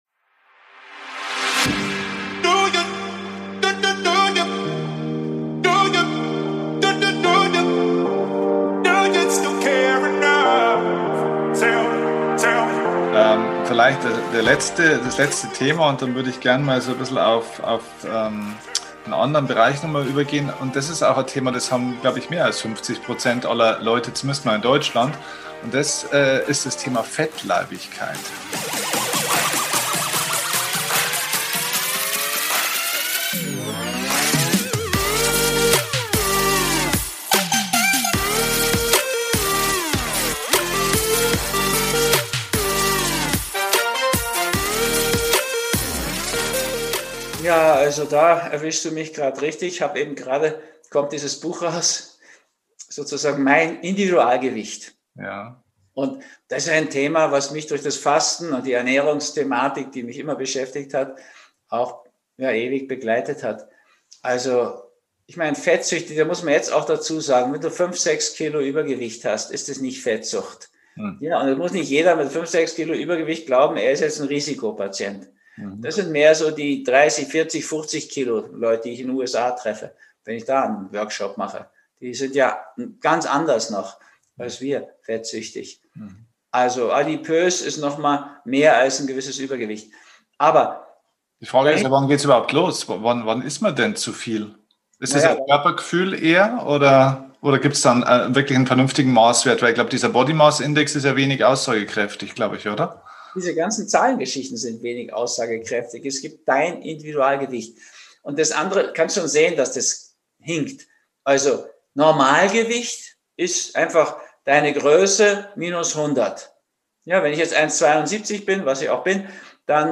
#361 Nie wieder krank durch Lebenswandel – Interview mit Dr. Rüdiger Dahlke TEIL 2